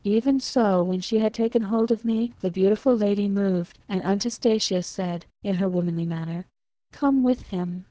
We take 100 test samples from the dev-clean subset of LibriTTS for testing.